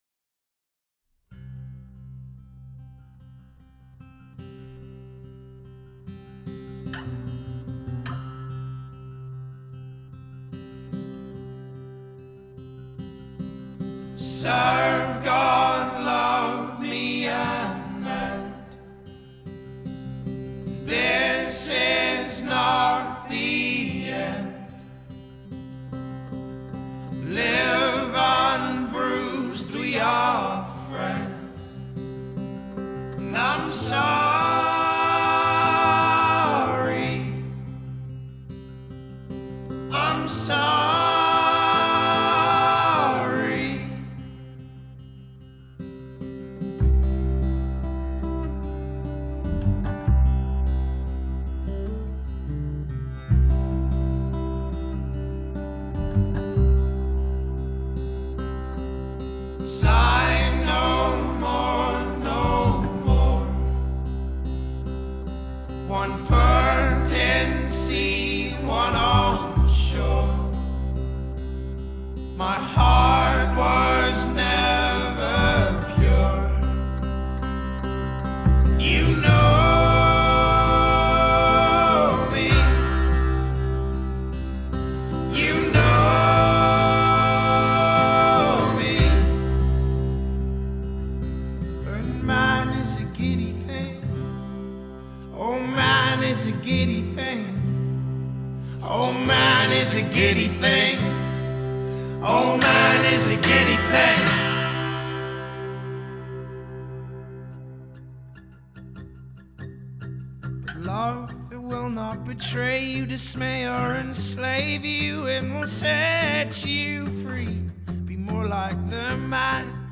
فولک راک
FolkRock، IndieFolk